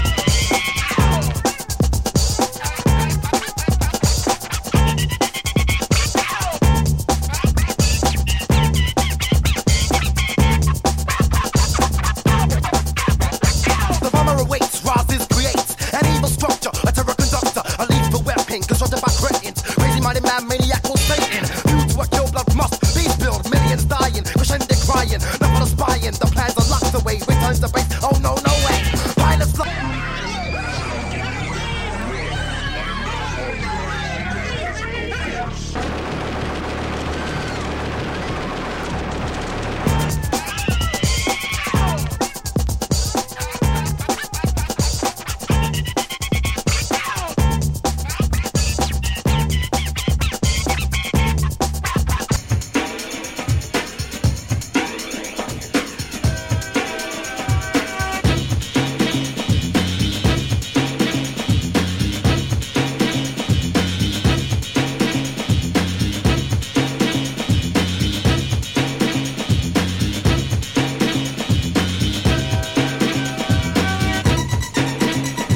Category: Hardcore
Britcore
Hip-Hop